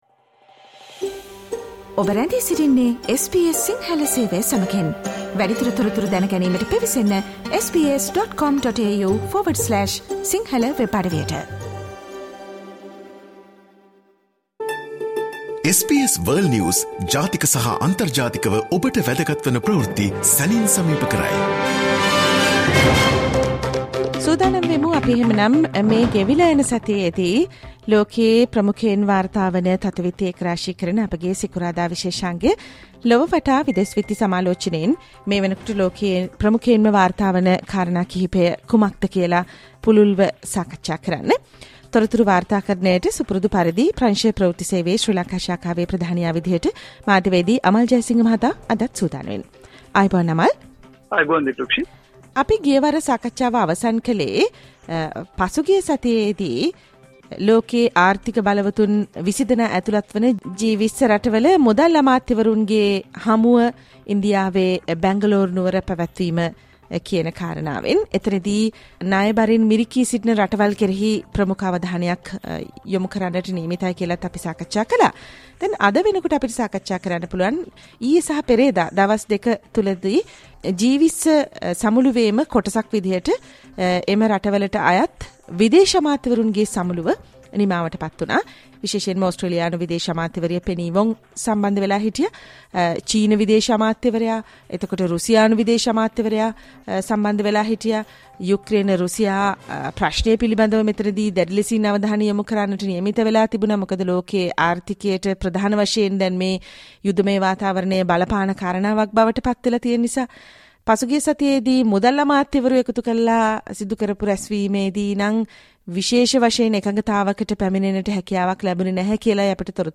World's prominent news highlights in 13 minutes - listen to the SBS Sinhala Radio weekly world News wrap every Friday